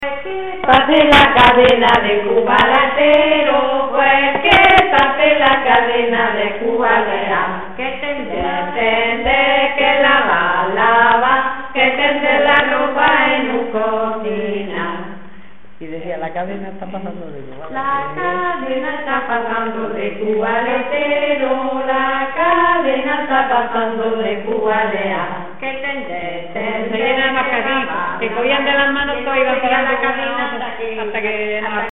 Materia / geográfico / evento: Canciones de la Candelaria Icono con lupa
Moraleda de Zafayona (Granada) Icono con lupa
Secciones - Biblioteca de Voces - Cultura oral